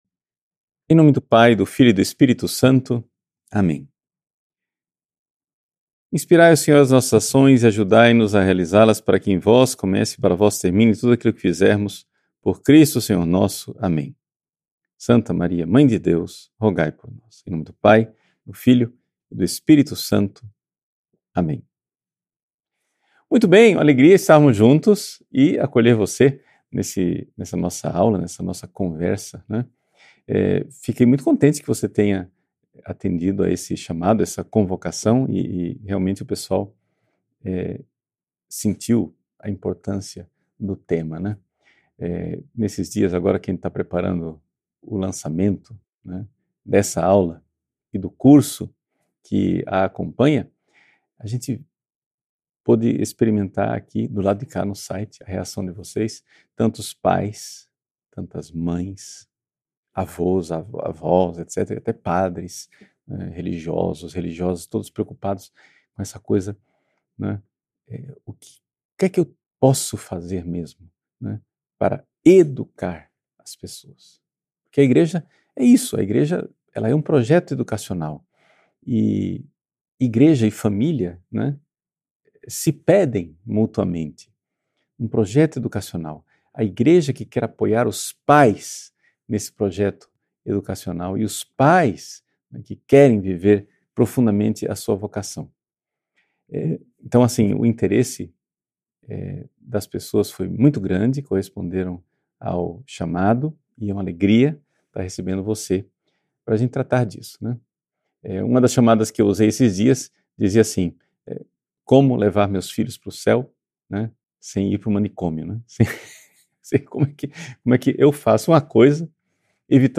Por que, tantas vezes, você perde a paciência com seus filhos e sente que eles atrapalham sua rotina? Educar seus filhos é apenas mais uma tarefa ou faz parte de quem você é? Nesta aula aberta